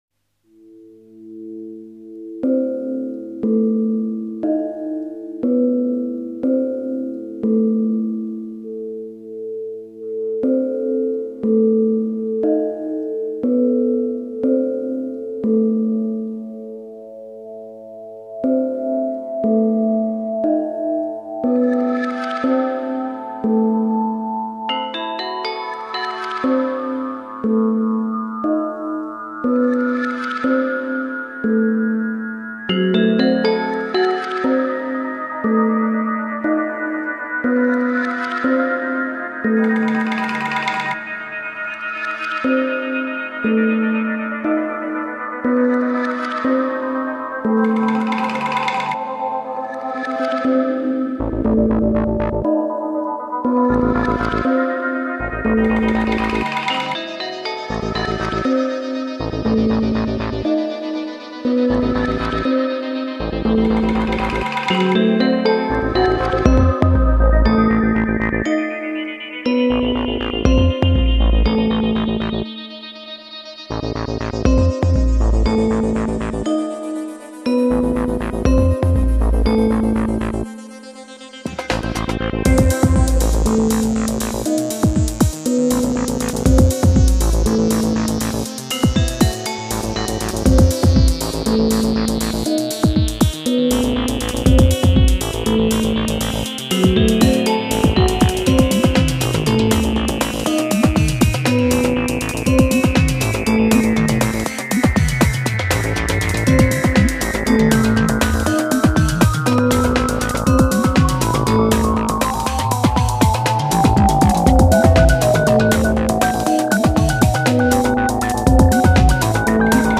所有的声音都很张狂、神秘,具有一种将我们送入精神航行的力量。